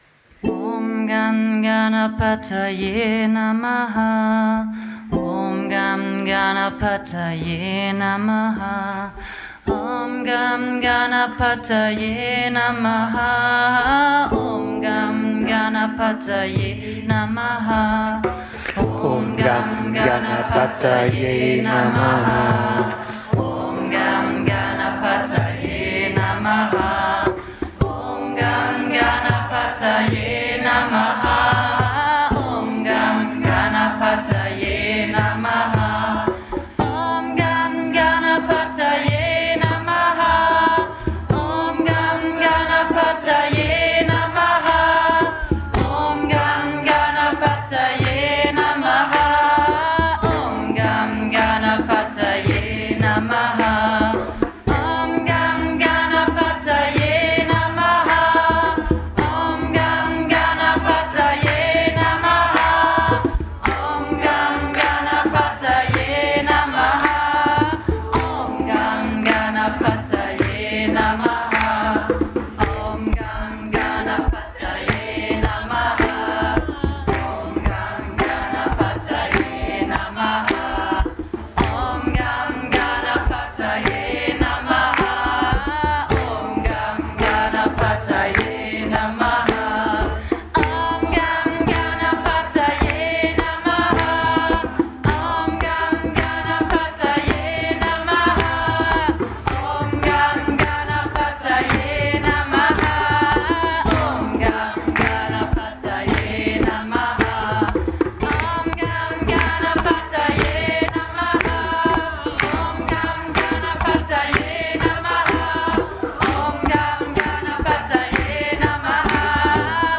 Om Gam Ganapataye Namaha mit neuen Yogalehrer:innen aus Bochum
Kirtan-Singen ist eine wunderbare Praxis, um das Herz zu öffnen und